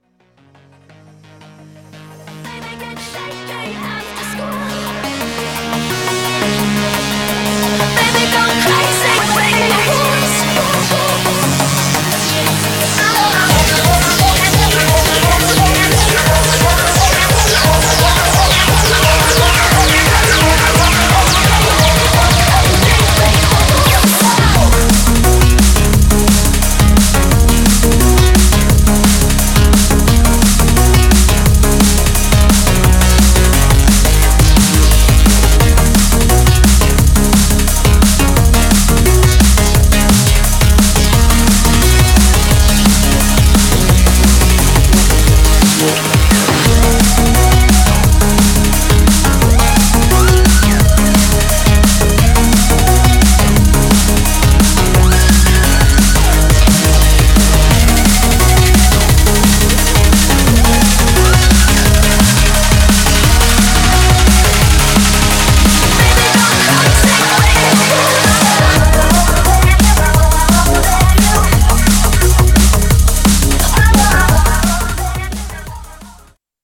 Styl: Drum'n'bass
* hraje velmi dobře